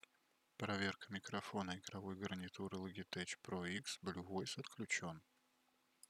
Голос звучит натурально, но «взрывные» (например, «п» и «т») и шипящие («с», «ф») звуки даются микрофону неидеально.
Тестовые записи